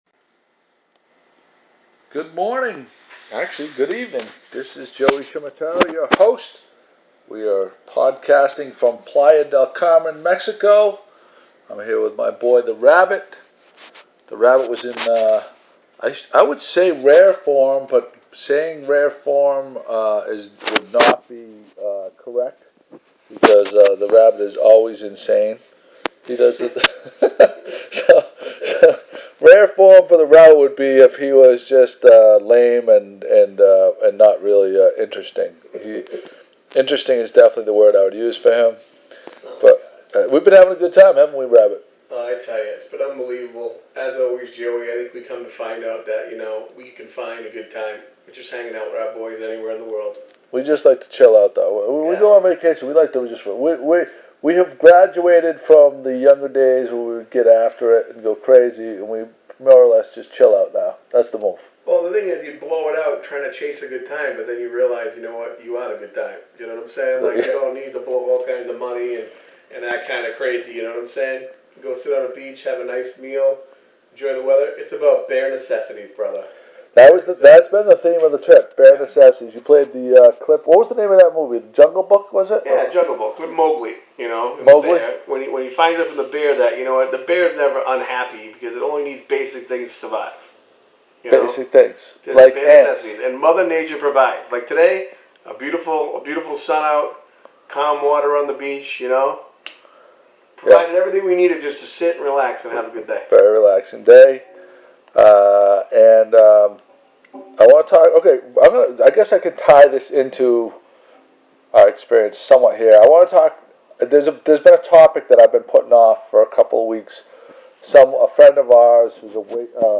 We’re taping with a simple iPad so I don’t have my editing tools.